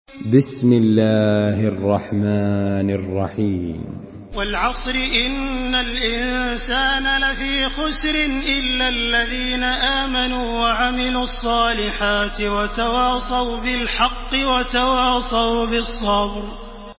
Surah Al-Asr MP3 in the Voice of Makkah Taraweeh 1429 in Hafs Narration
Murattal